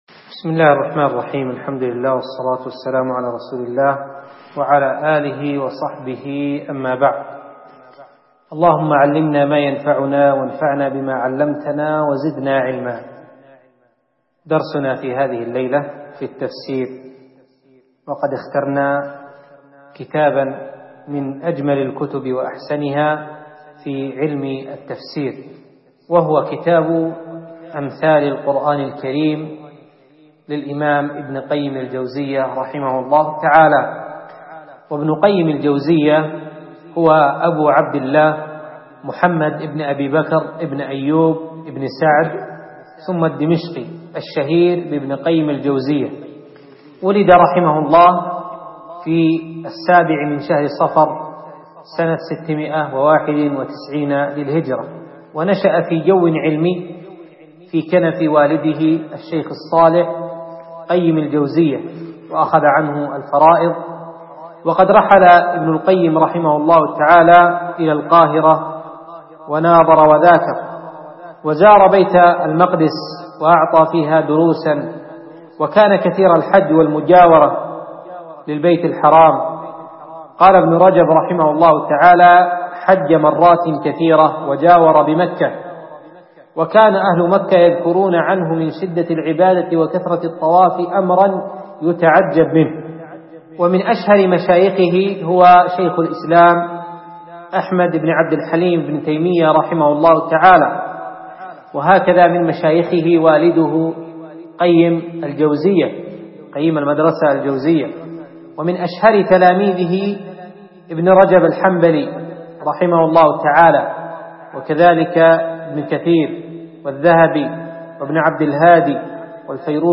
أمثال القرآن (الدرس اأول ).mp3